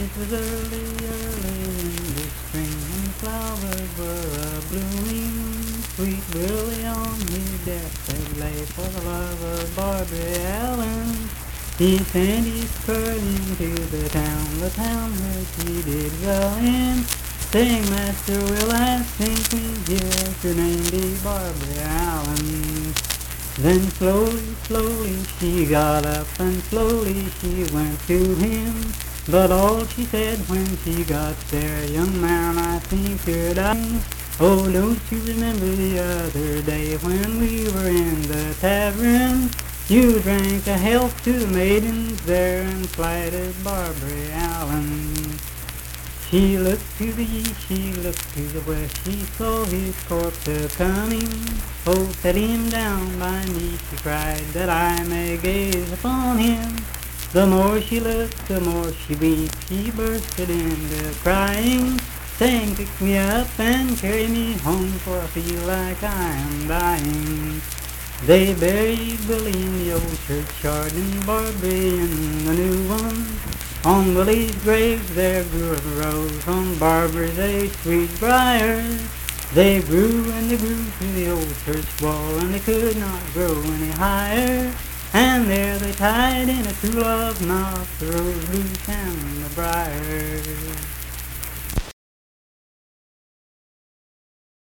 Unaccompanied vocal music
Verse-refrain 4(8).
Performed in Frametown, Braxton County, WV.
Voice (sung)